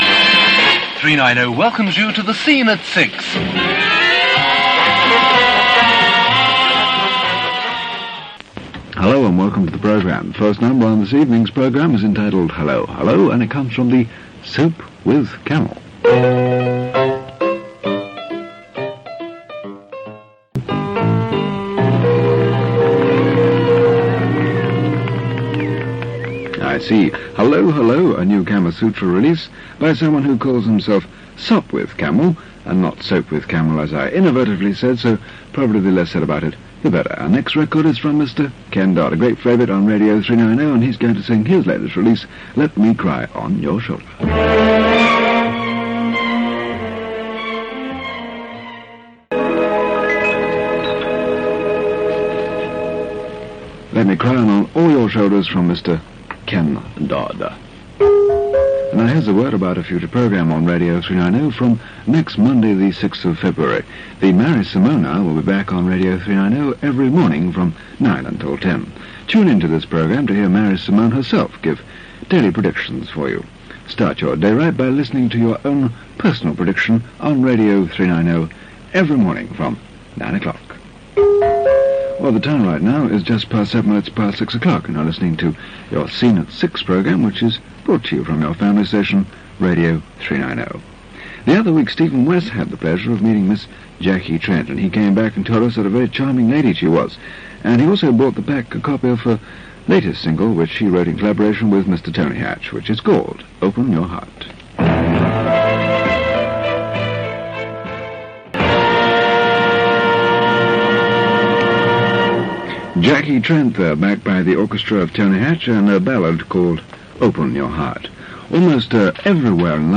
on Radio 390's ‘Scene At Six’ programme from 30th January 1967
This is an edited version of a recording made available by The Offshore Radio Archive (duration 3 minutes 43 seconds)